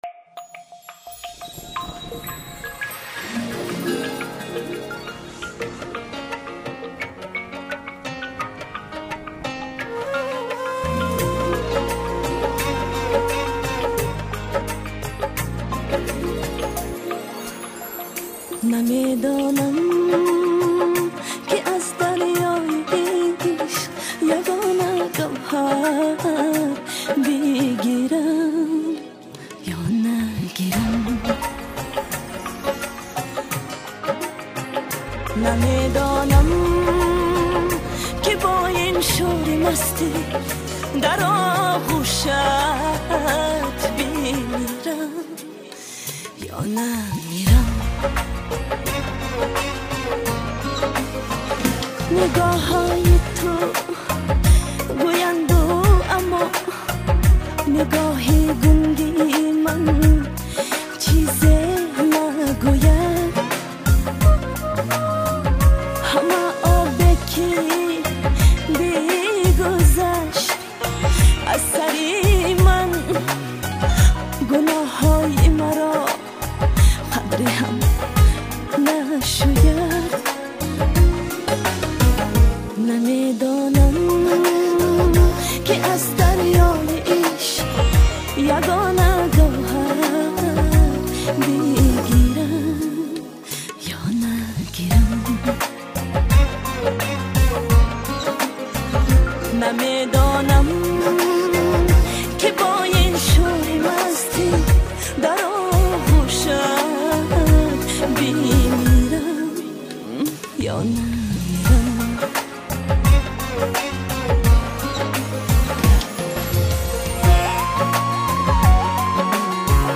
Главная » Файлы » Каталог Таджикских МР3 » Эстрада